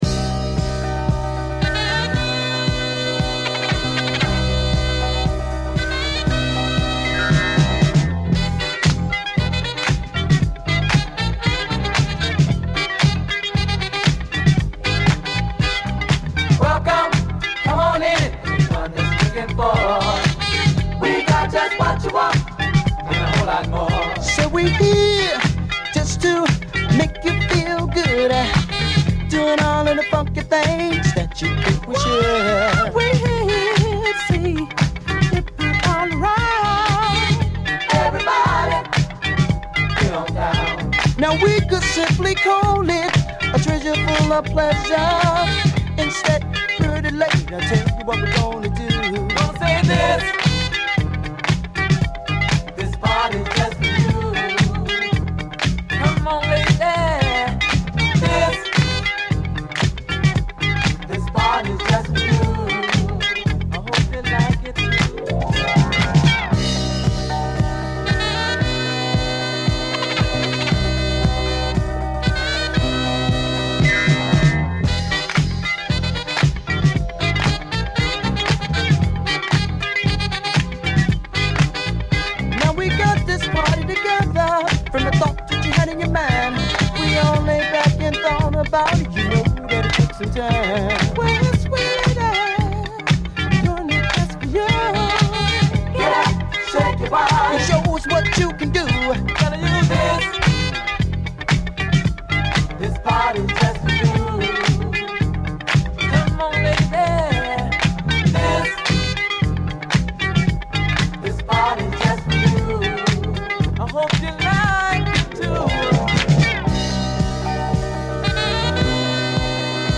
disco & boogie bangers